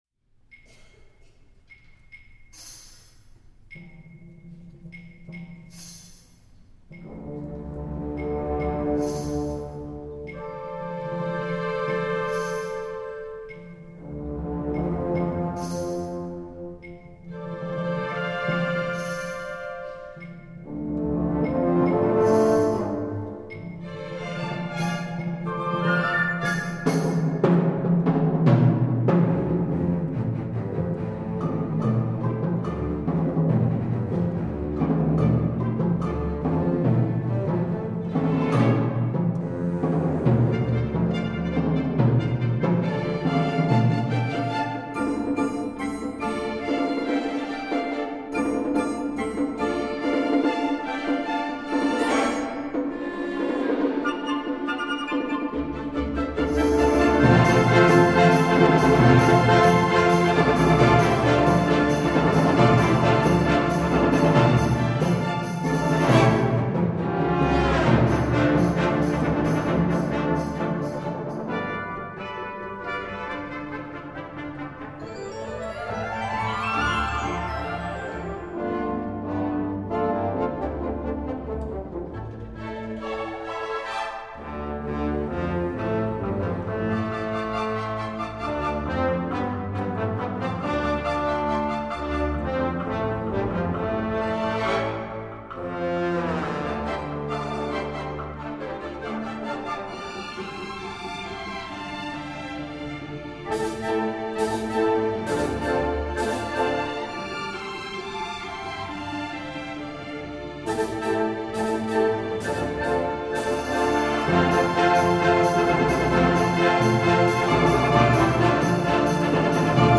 for Orchestra (2007)